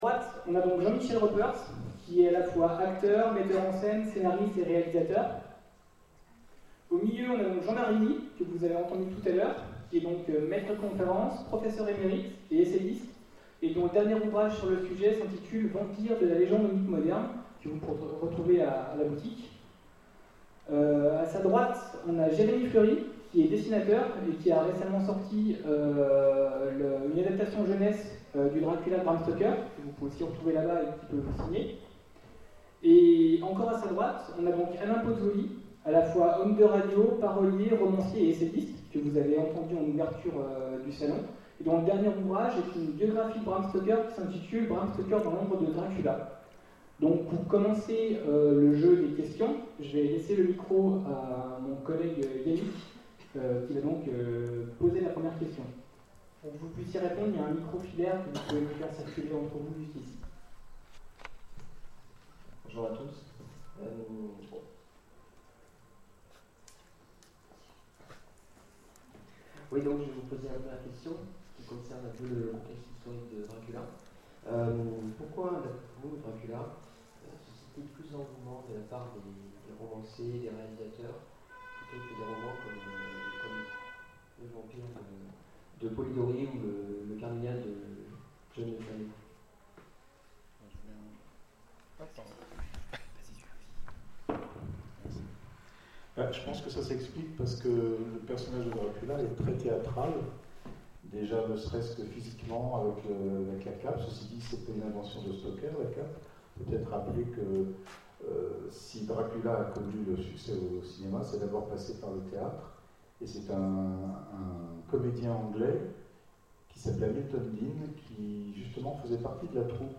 Salon du Vampires 2012 : Table ronde – Adapter Dracula
Mots-clés Bram Stoker Vampire Conférence Partager cet article